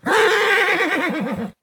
CosmicRageSounds / ogg / general / combat / creatures / horse / he / prepare1.ogg